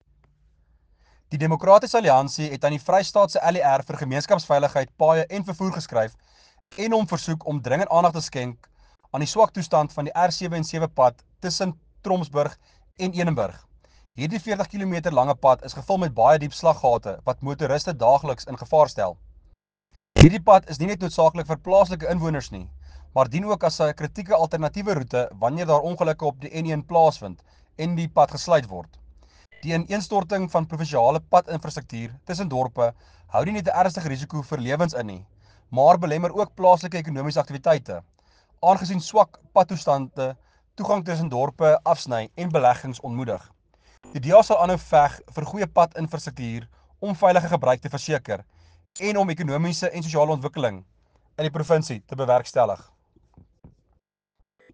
Afrikaans soundbites by Werner Pretorius MPL and